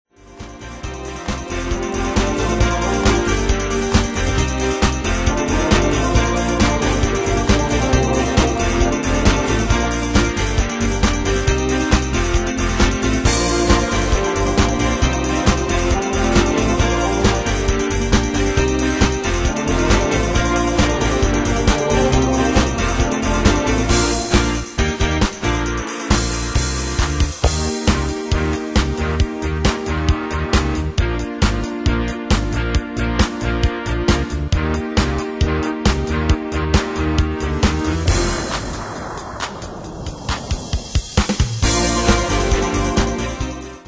Style: Dance Année/Year: 2001 Tempo: 135 Durée/Time: 3.21
Pro Backing Tracks